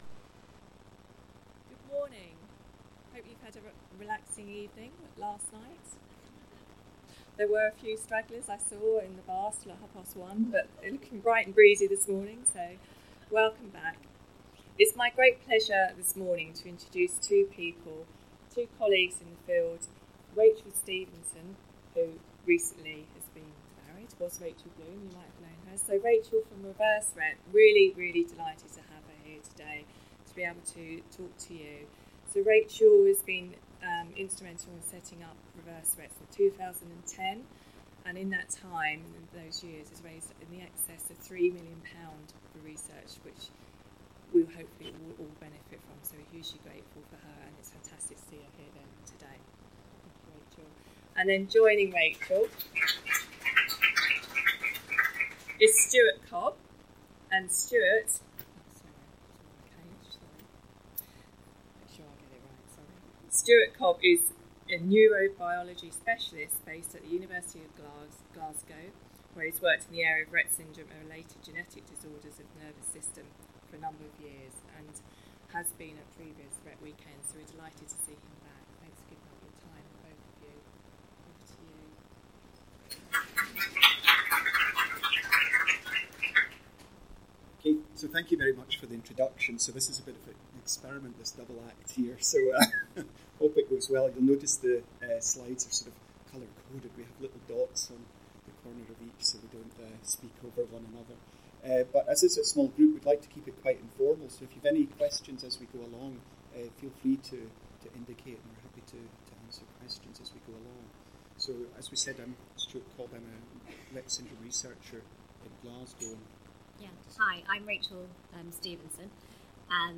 main-room-closing-speech-retts.mp3